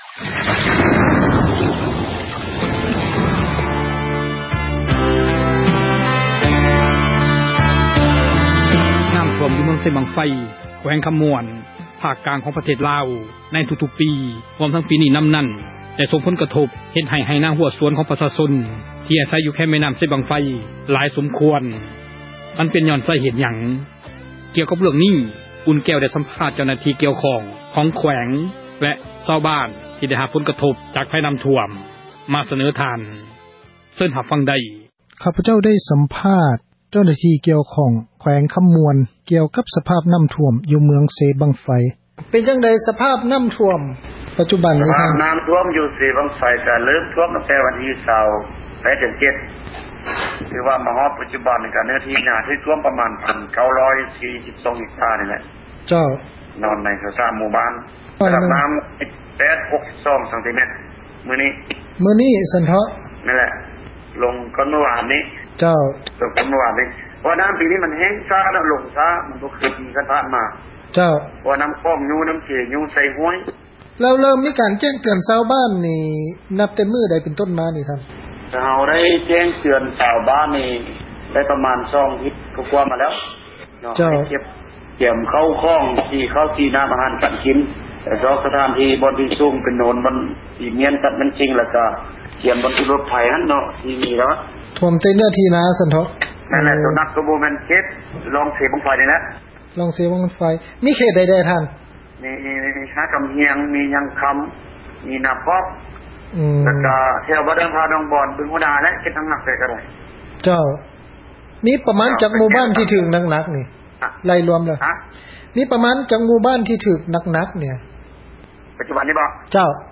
Citizen journalist